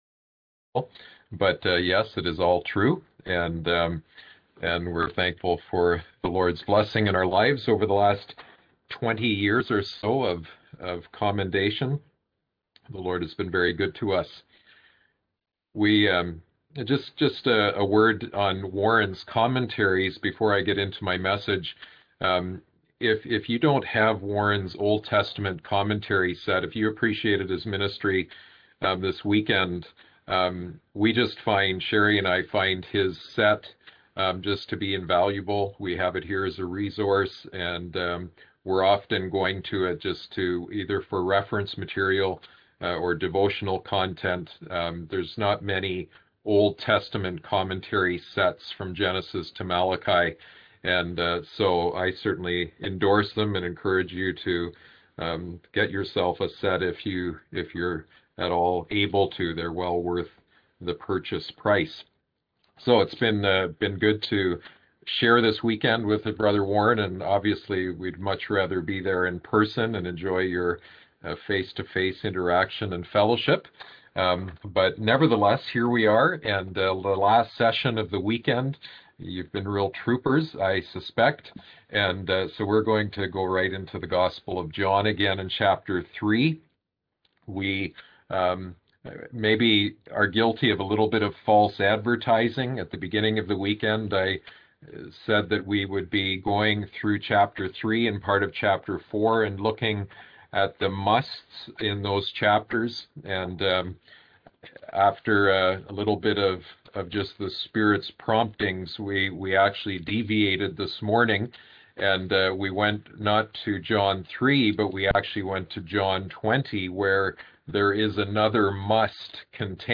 Passage: John 3 Service Type: Seminar